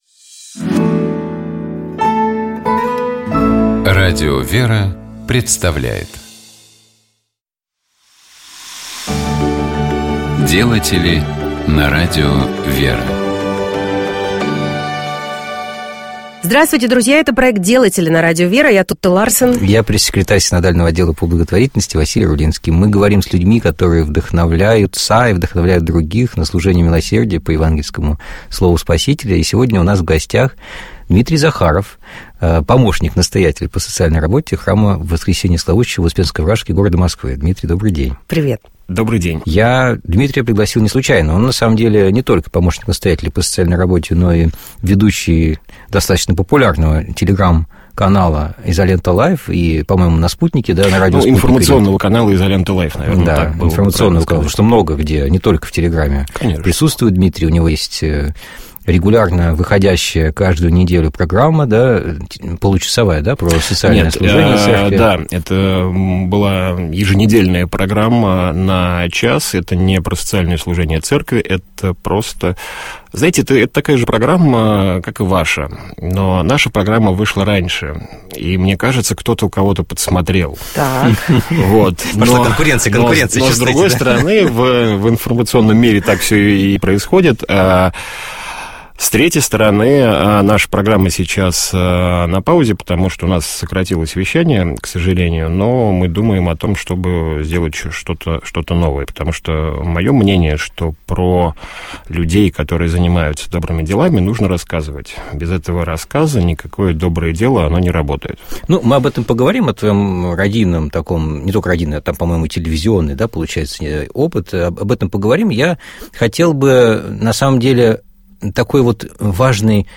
Ведущие программы: Тутта Ларсен